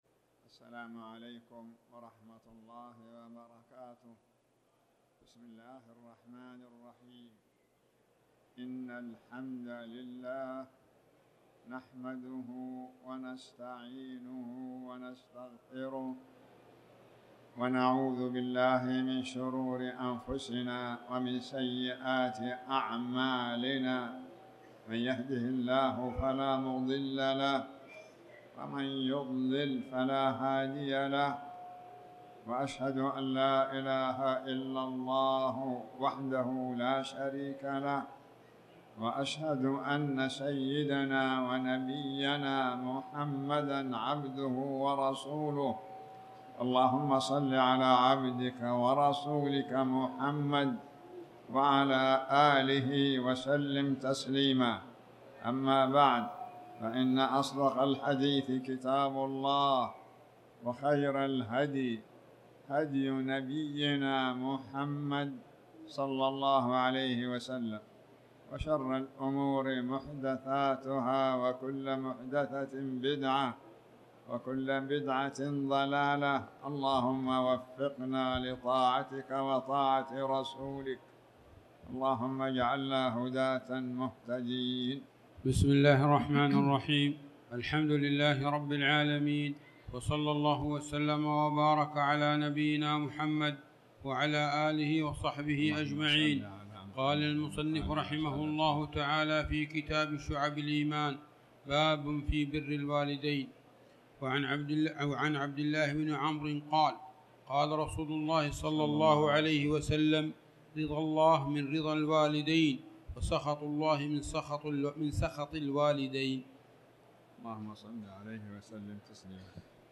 تاريخ النشر ١٥ شعبان ١٤٣٩ هـ المكان: المسجد الحرام الشيخ